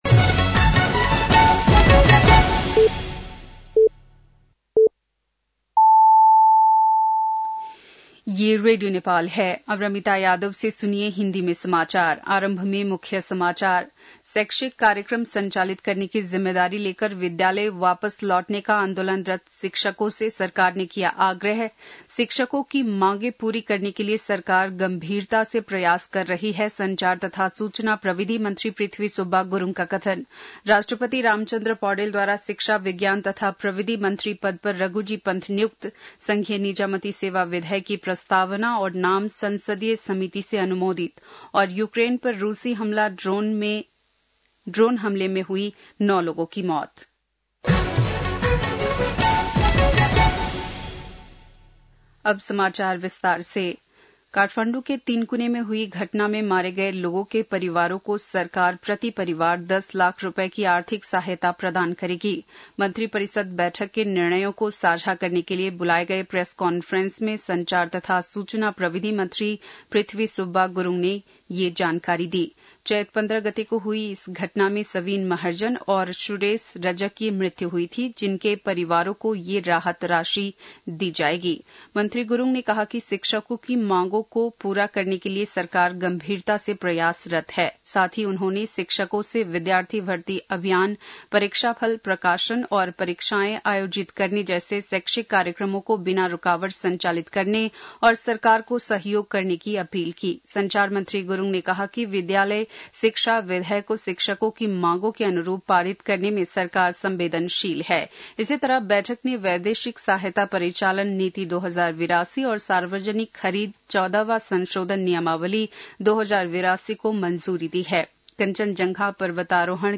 बेलुकी १० बजेको हिन्दी समाचार : १० वैशाख , २०८२
10-pm-news.mp3